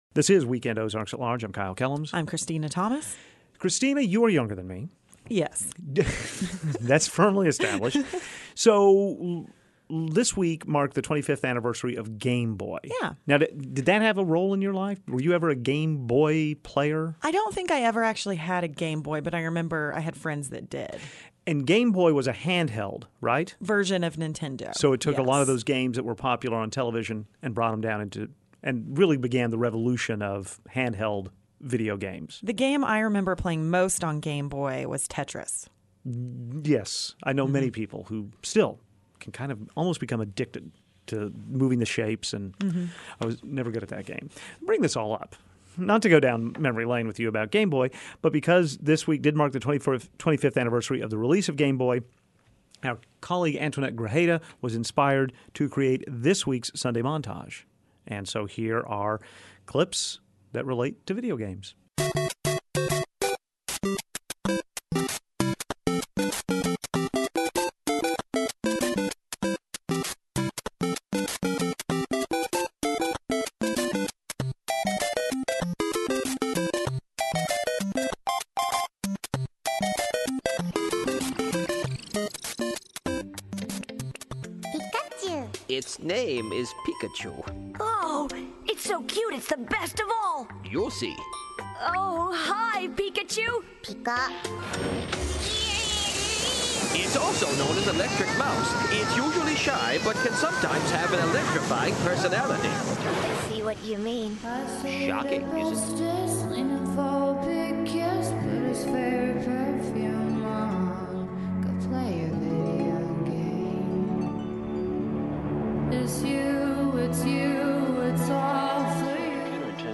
Clips from today's montage include: